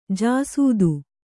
♪ jāsūdu